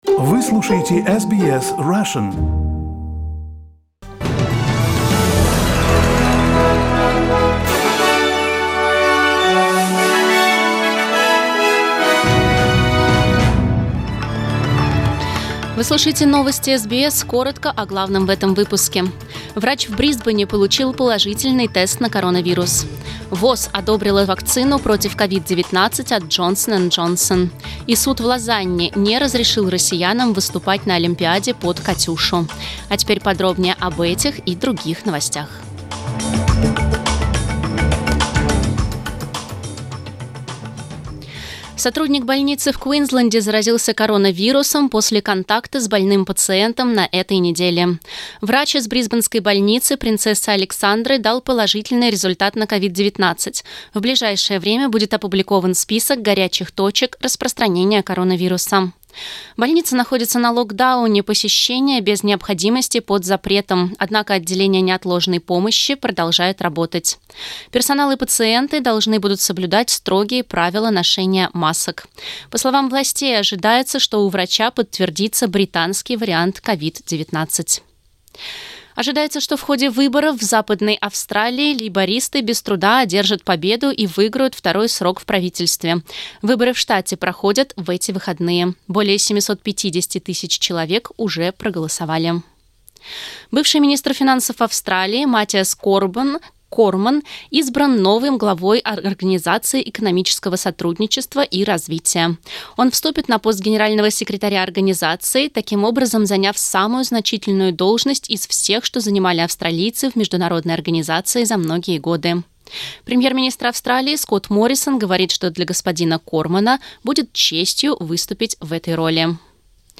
Новостной выпуск за 13 марта